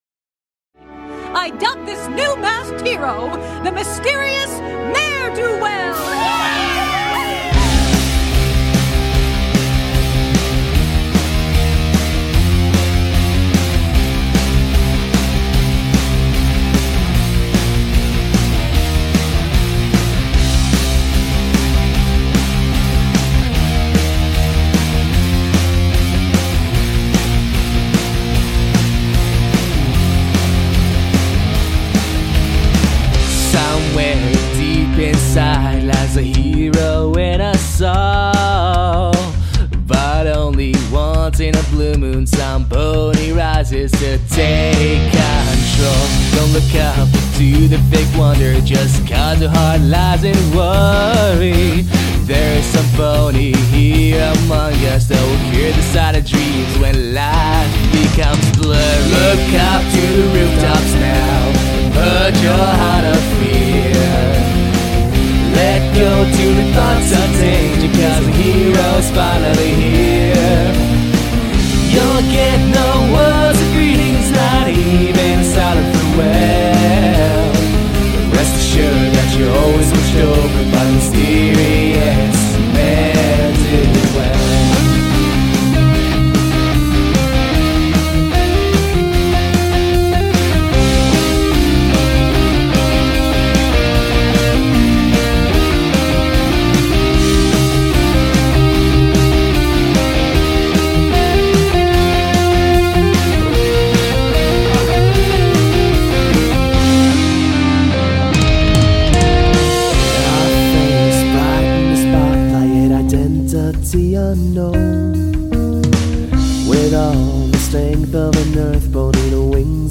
Drums